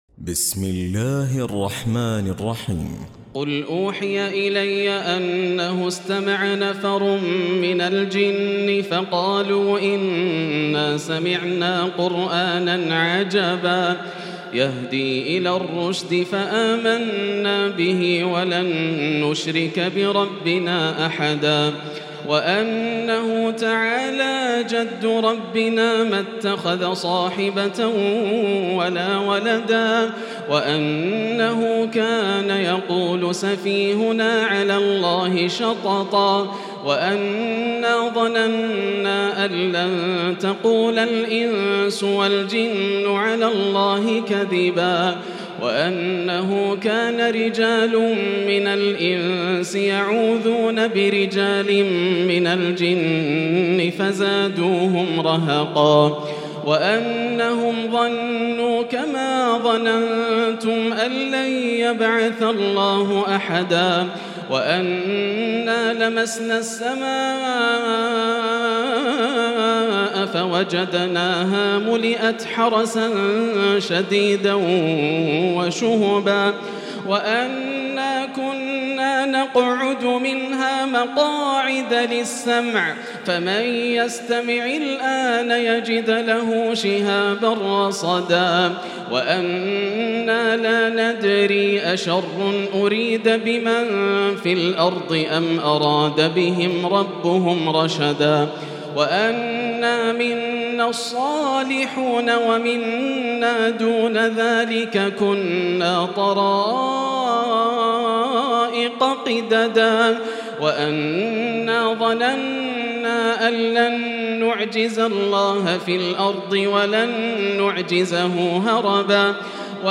تراويح ليلة 28 رمضان 1438هـ من سورة الجن الى المرسلات Taraweeh 28 st night Ramadan 1438H from Surah Al-Jinn to Al-Mursalaat > تراويح الحرم المكي عام 1438 🕋 > التراويح - تلاوات الحرمين